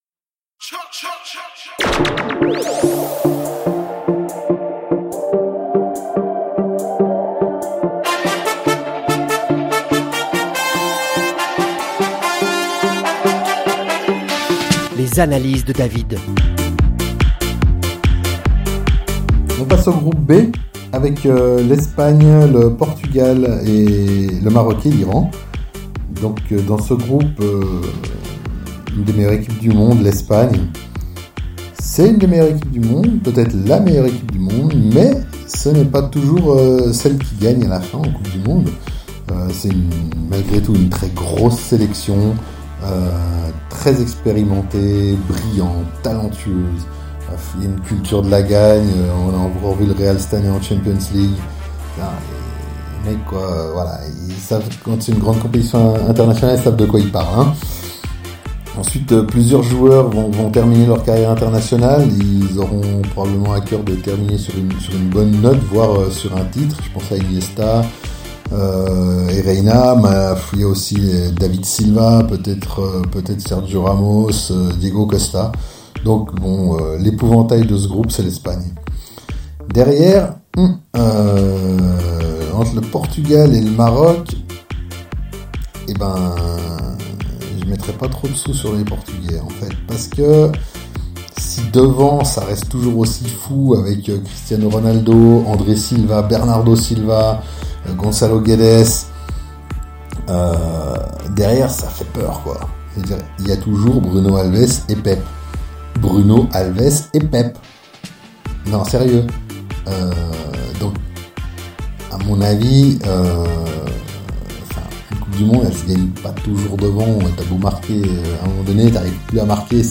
avec sarcasme et polémique.